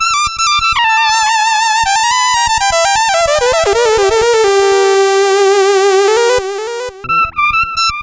Synth 17.wav